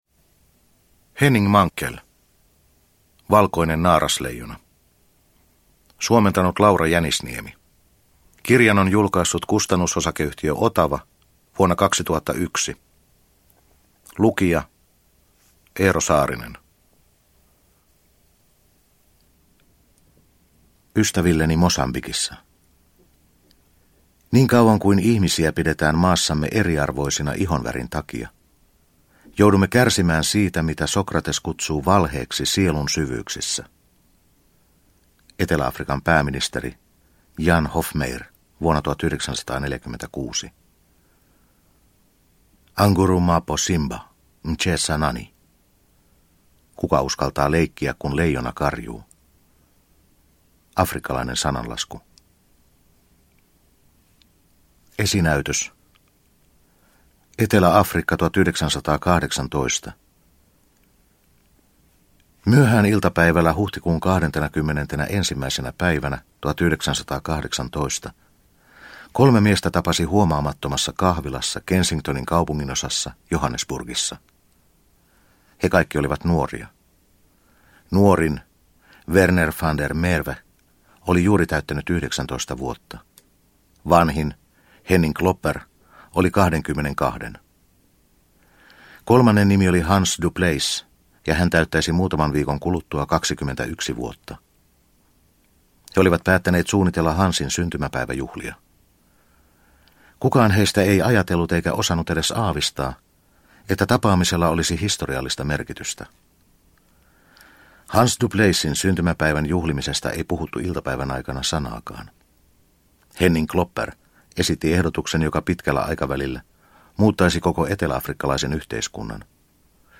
Valkoinen naarasleijona – Ljudbok – Laddas ner